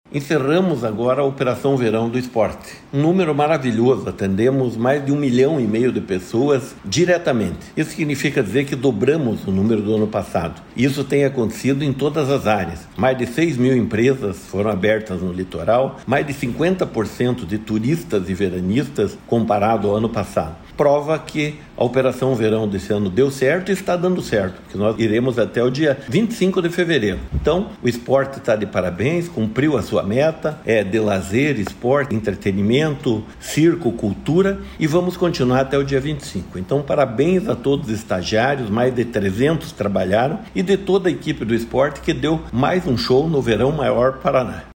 Sonora do secretário do Esporte, Helio Wirbiski, sobre o fim das atividades do esporte no Verão Maior Paraná 2023/2024